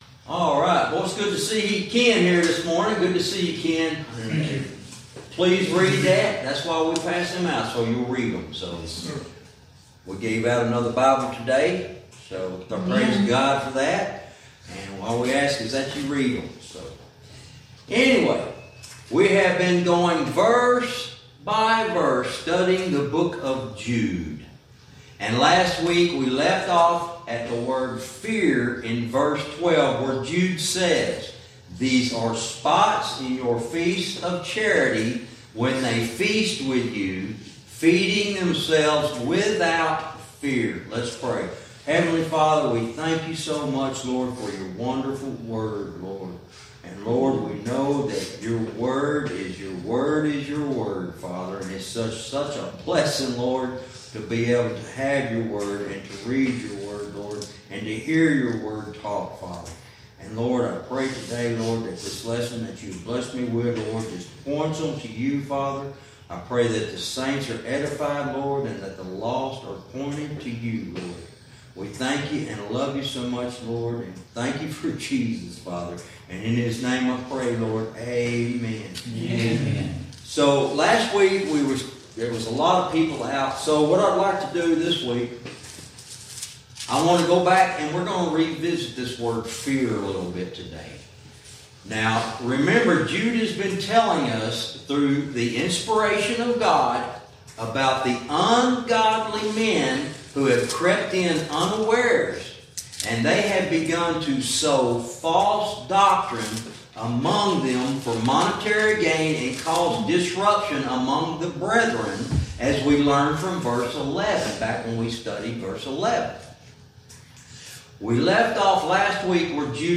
Verse by verse teaching - Lesson 48 verse 12